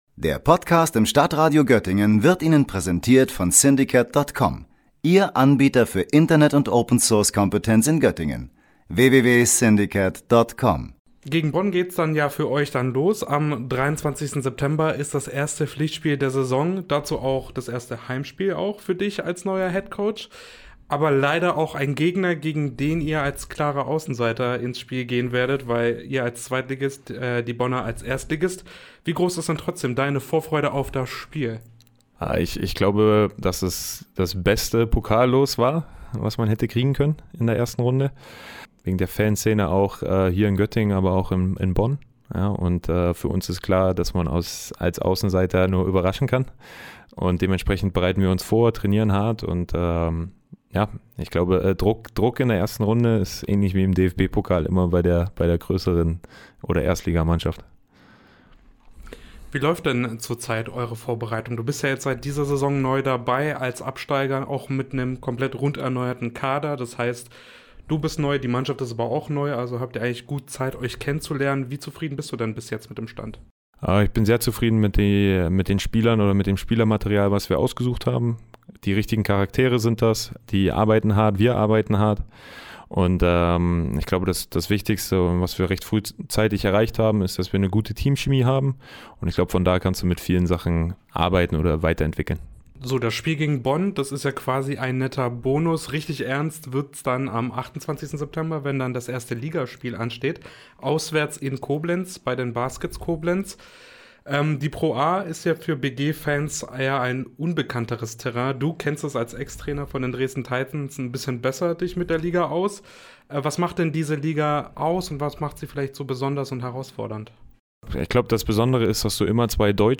Kleine Notiz, das Interview fand vor dem EM-Achtelfinale statt.